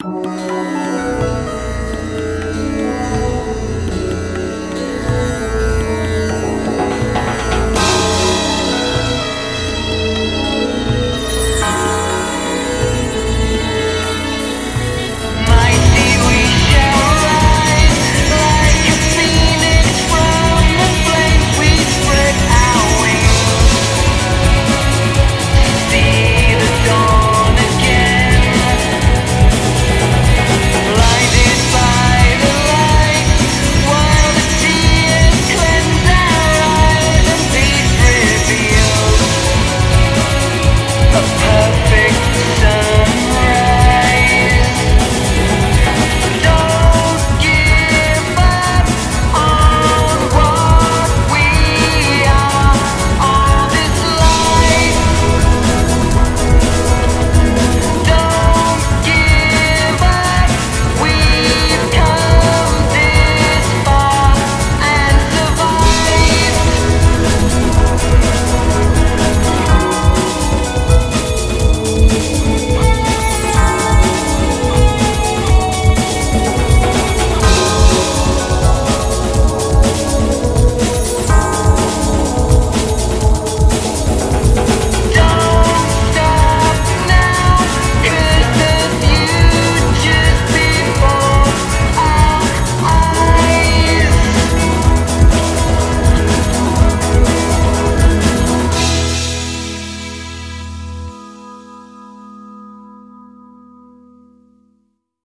BPM124
Audio QualityPerfect (High Quality)
[PSYCHEDELIC]